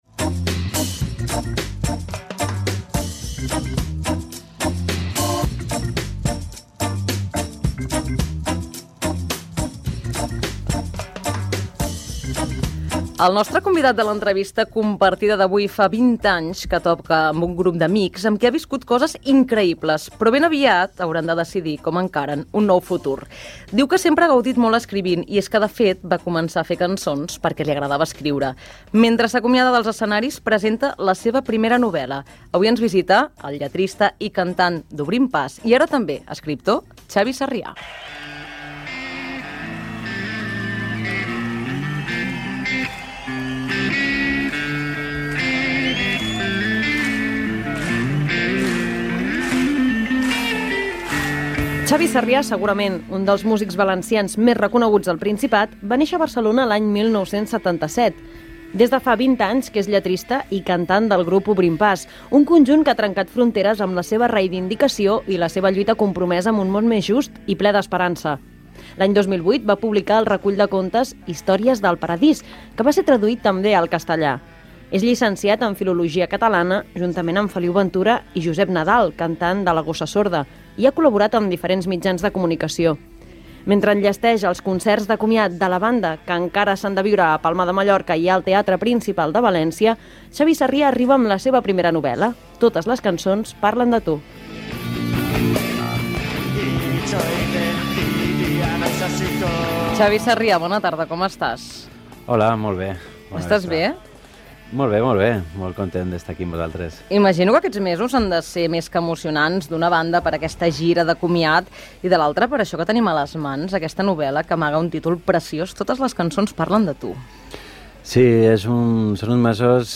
Espai L'entrevista compartida